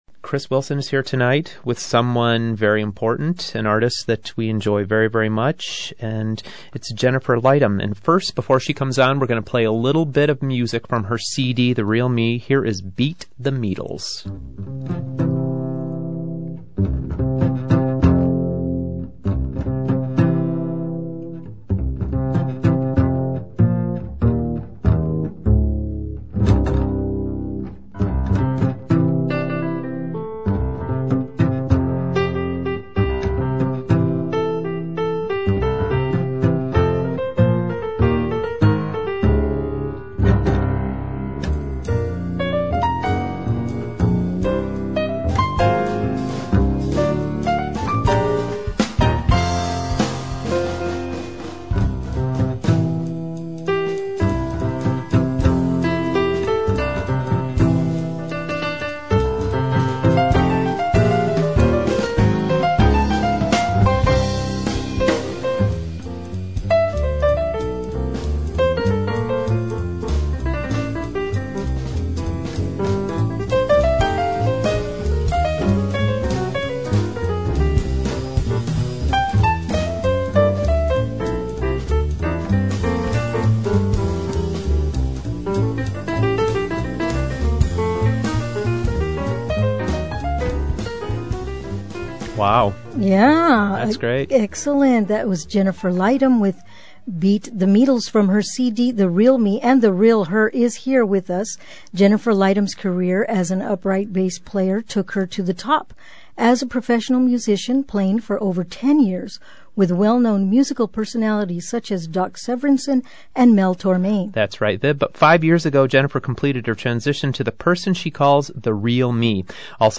And of course I slip in some other fun music at the start, ranging from a children's song to a San Francisco chantuse.